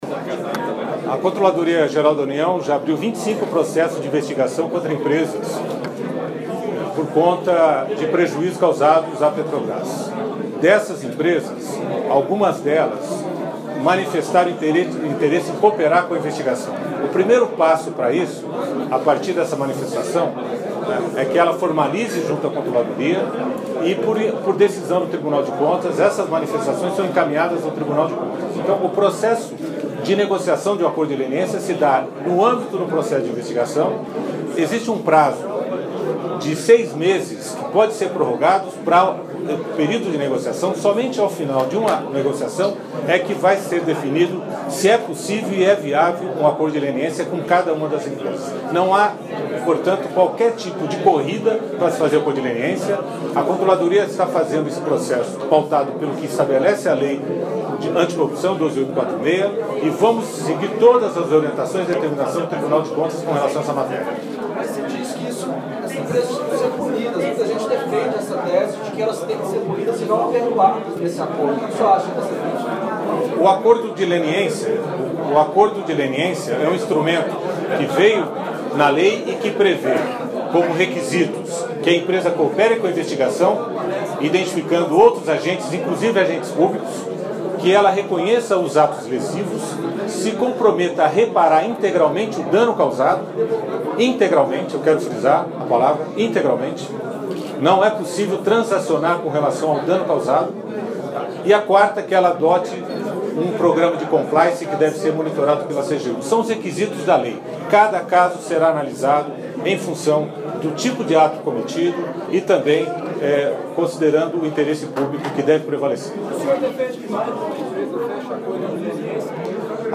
Áudio da entrevista concedida pelo Ministro Valdir Simão no âmbito da Operação Lava Jato — Controladoria-Geral da União